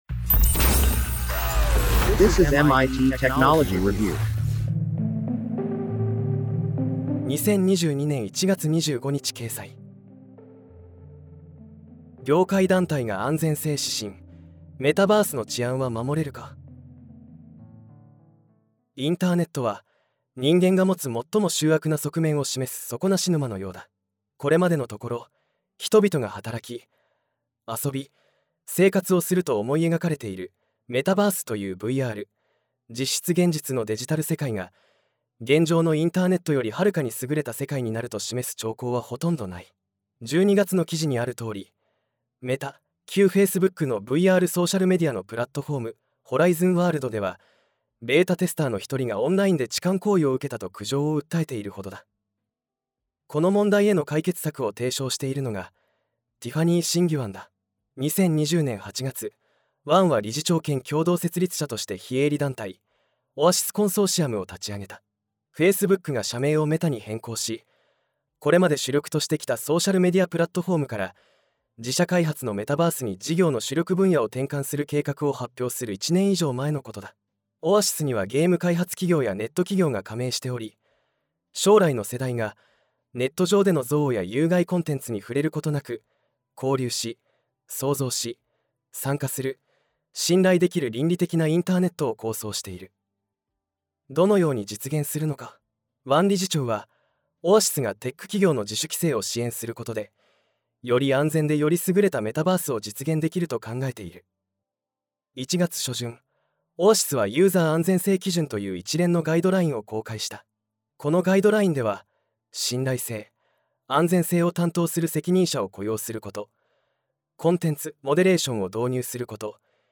なお、本コンテンツは音声合成技術で作成しているため、一部お聞き苦しい点があります。